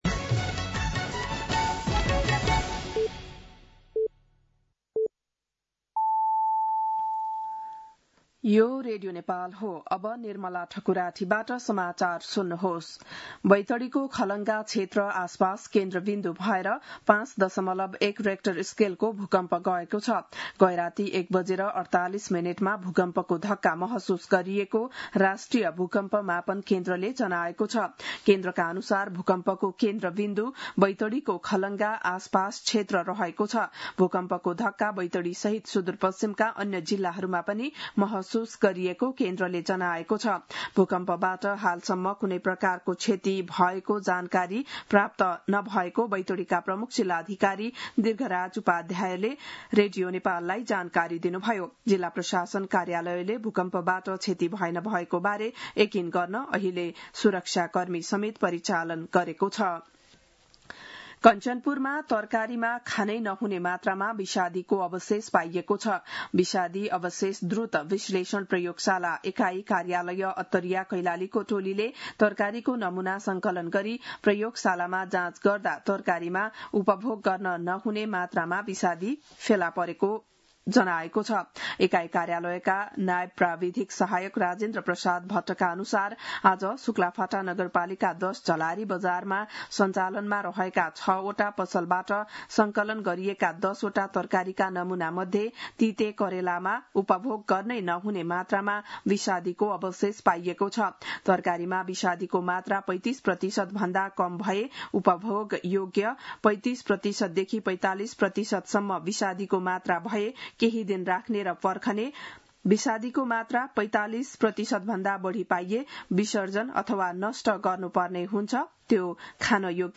बिहान ११ बजेको नेपाली समाचार : ९ जेठ , २०८२